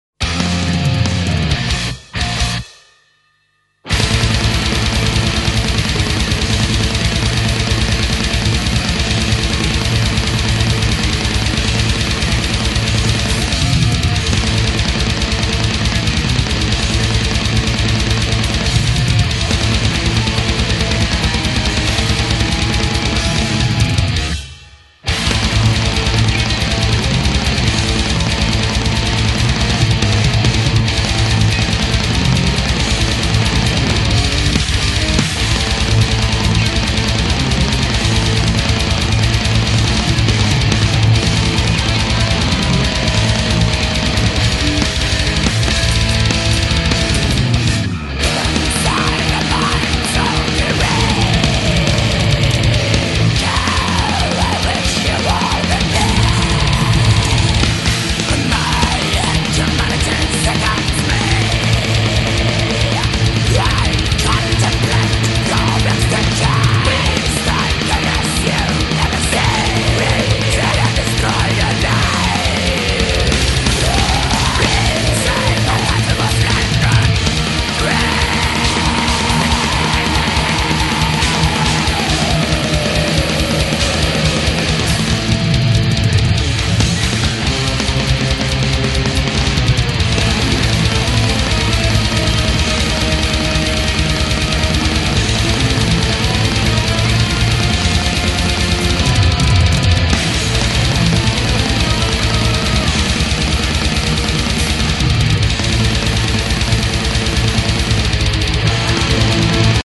ТHRASH TIL DEATH